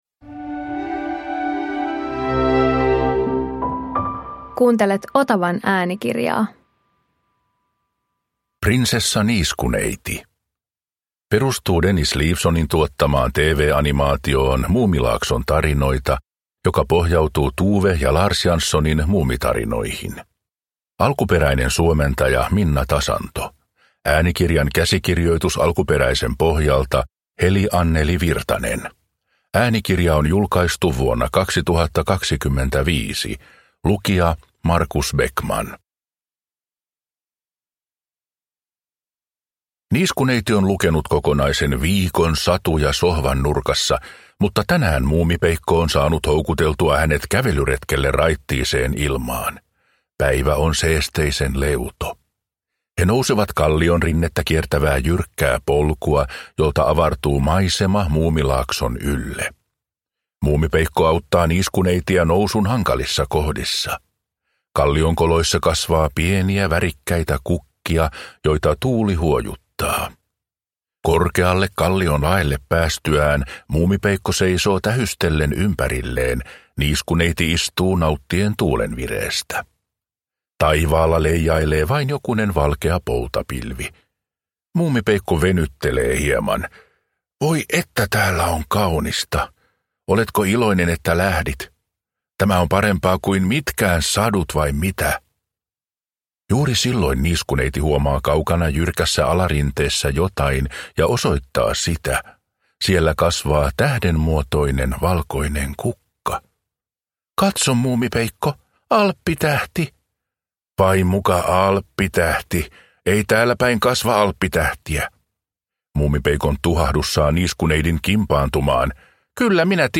Muumi - Prinsessa Niiskuneiti – Ljudbok